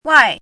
“外”读音
wài
外字注音：ㄨㄞˋ
国际音标：wĄi˥˧
wài.mp3